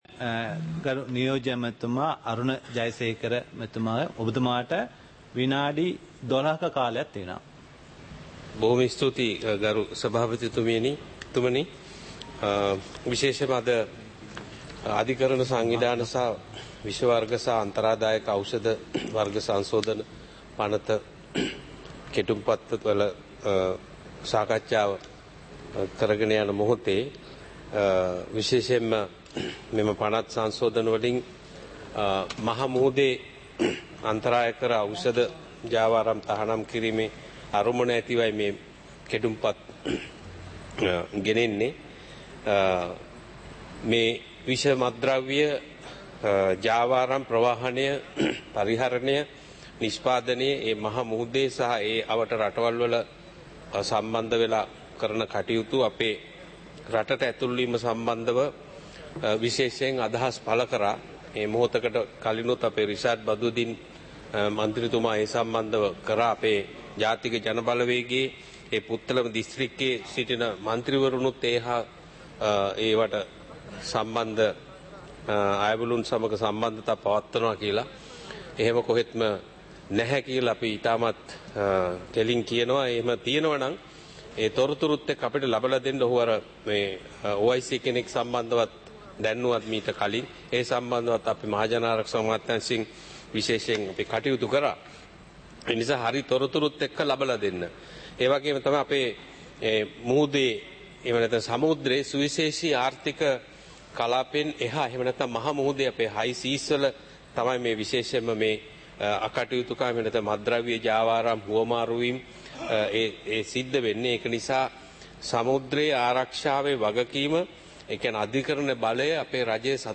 நேரலை - பதிவுருத்தப்பட்ட